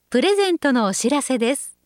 ホームページ作成で利用できる、さまざまな文章や単語を、プロナレーターがナレーション録音しています。
ナレーション： プレゼントのお知らせです 自動椅子 日産の自動椅子、なんとも動画はユニークで、かなりのアクセスがある。